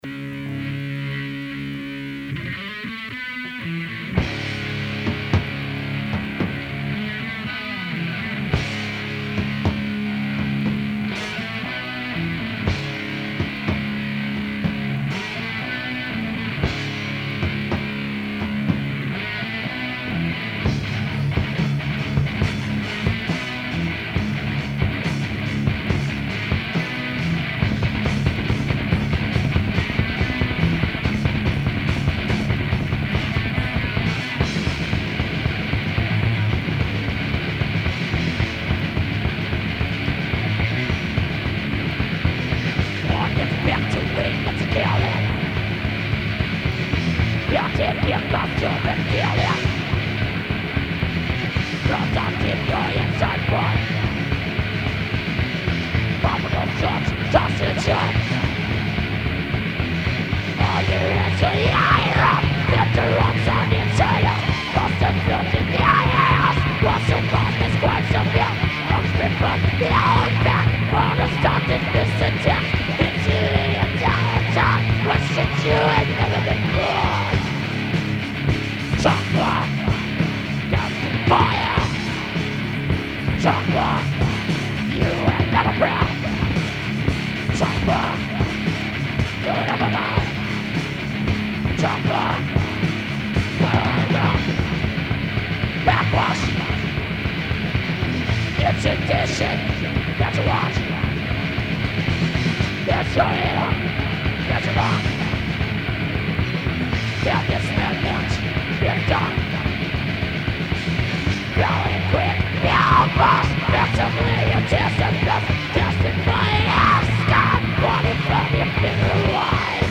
Vocals
Guitar
Bass
Drums
Filed under: Metal